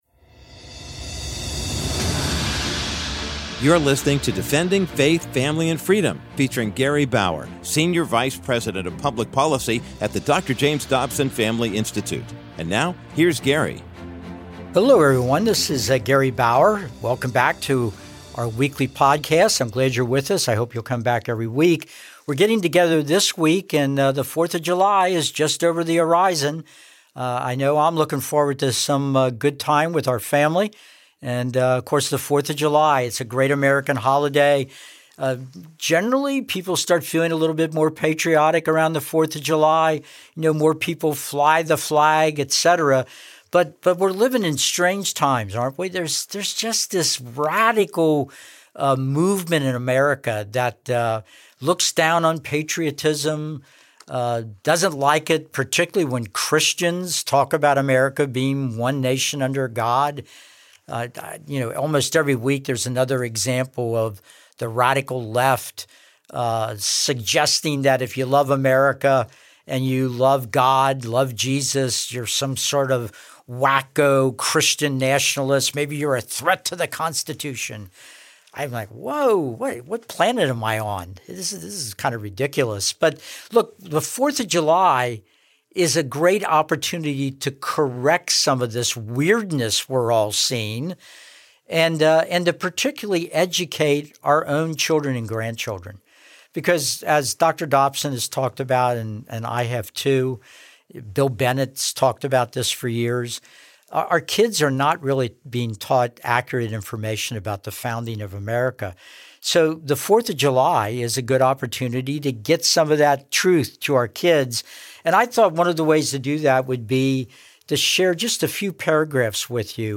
To tell this story, Bauer plays clips from the historical documentary We the People, with a professional actor who plays Franklin.